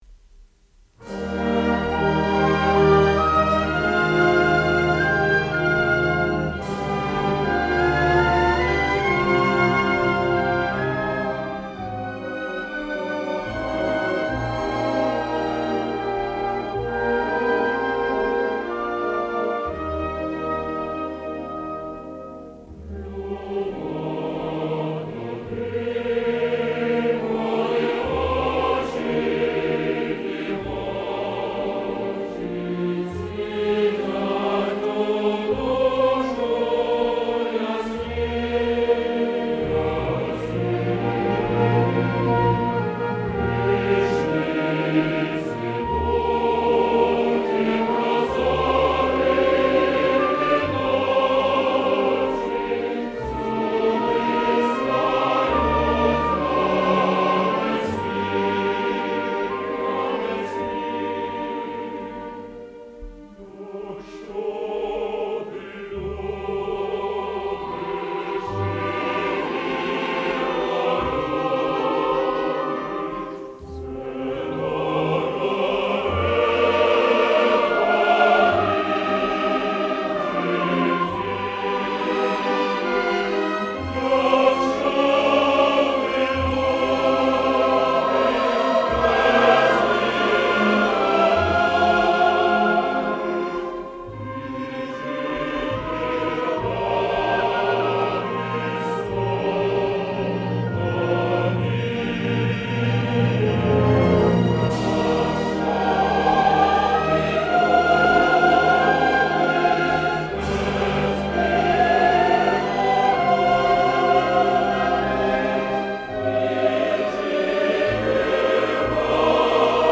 Очень красивая и воодушевляющая песня о Советской Украине.